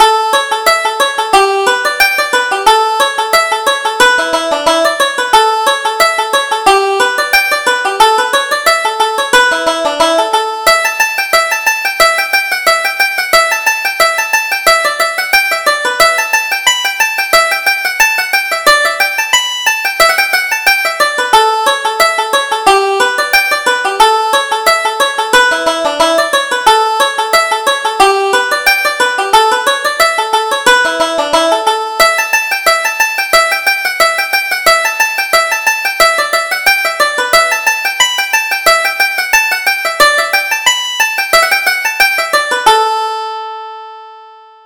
Reel: The Green Groves of Erin (Version 2)